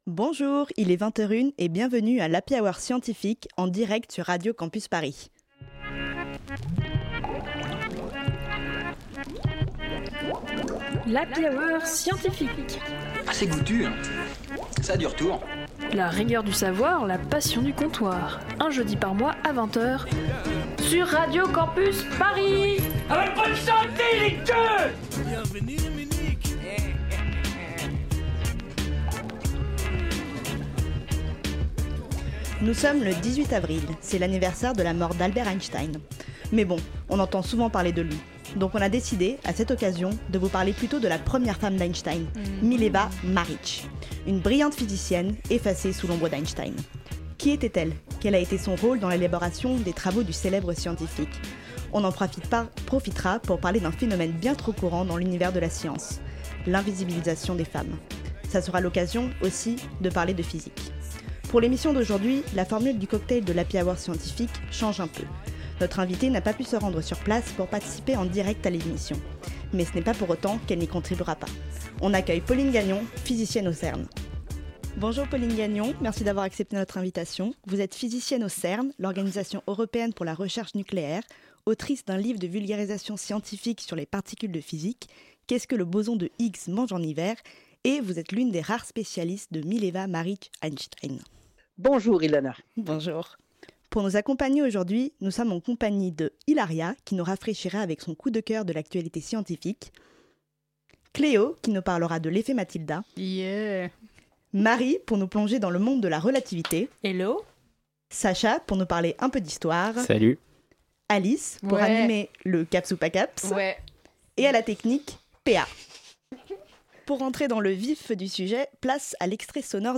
Pour l’émission d’aujourd’hui, la formule du cocktail de l’Happy Hour Scientifique change un peu : notre invitée n’a pas pu se rendre sur place pour participer en direct à l’émission, mais ce n’est pas pour autant qu’elle n’y contribuera pas.